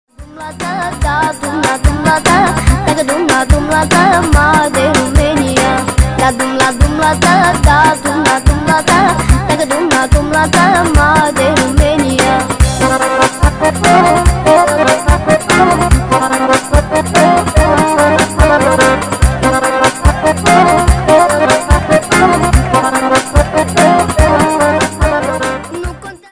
детский голос